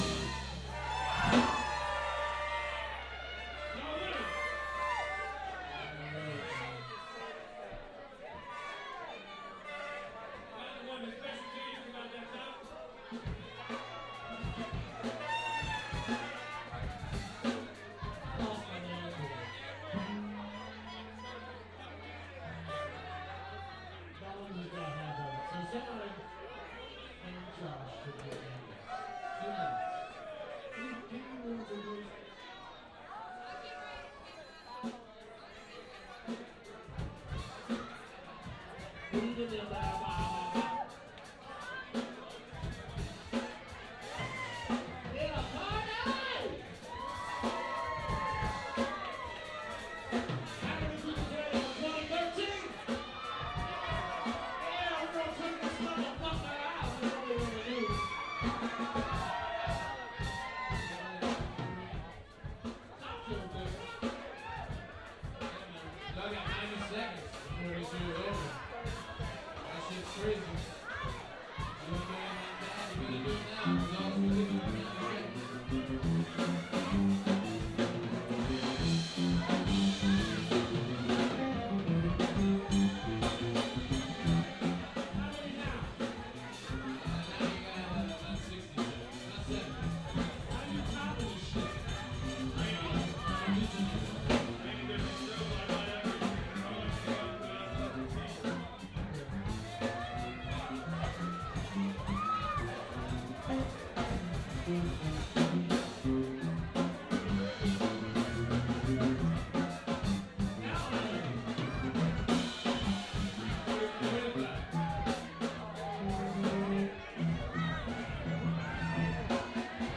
Recorded at Foster's Sports Bar in Beckley, WB, with an iPhone5, Tascam IXJ2, and Roland CS-10em microphones. I was a little conservative with levels.